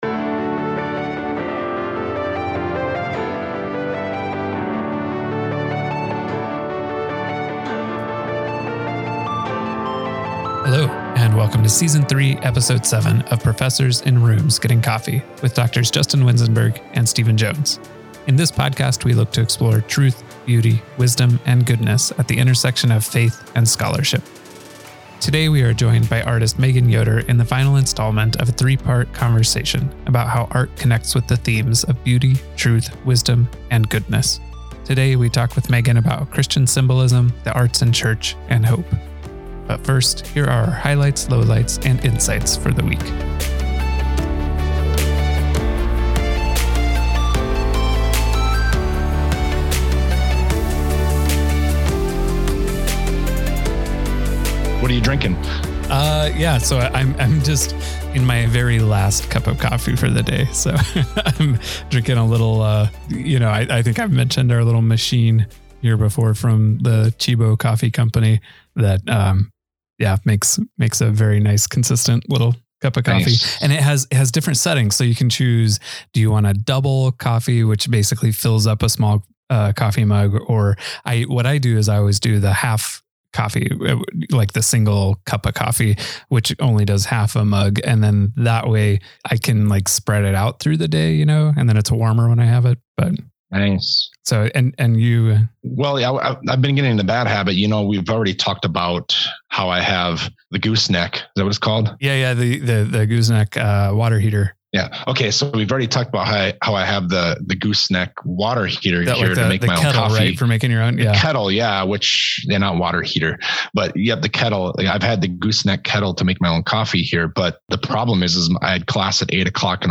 This episode was partially recorded on the land of the Wahpekute